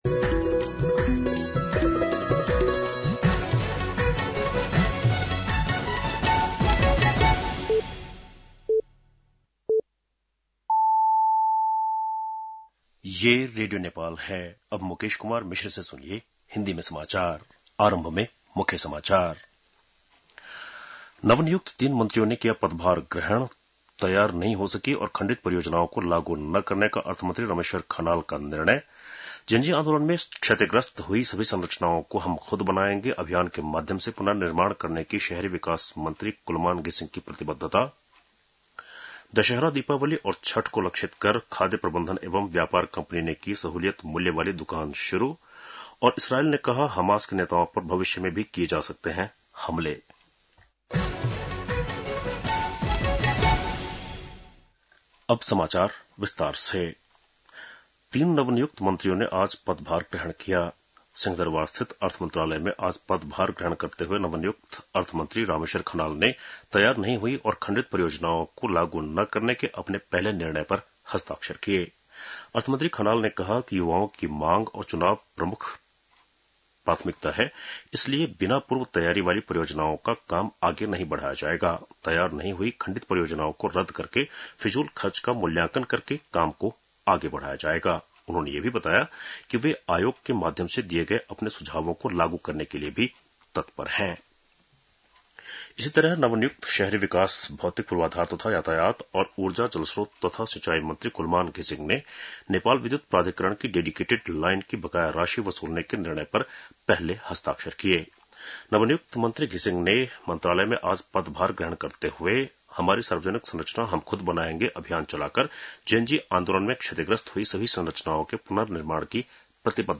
बेलुकी १० बजेको हिन्दी समाचार : ३० भदौ , २०८२